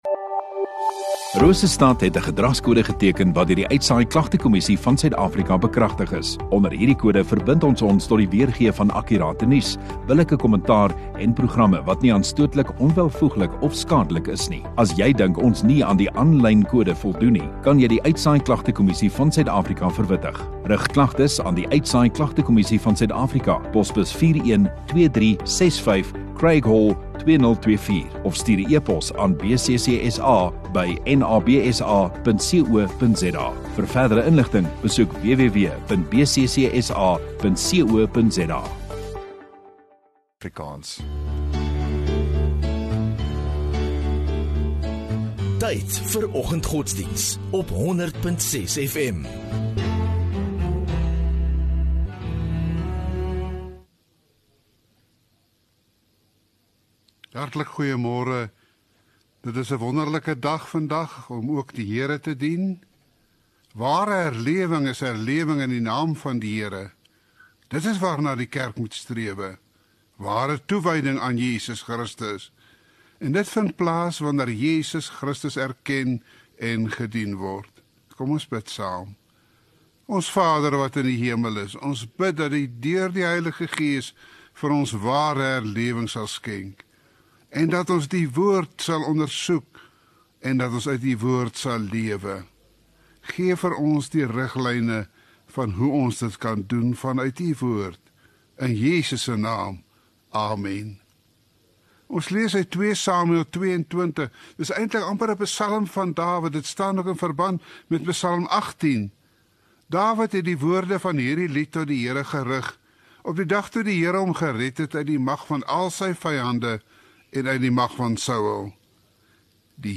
19 Sep Vrydag Oggenddiens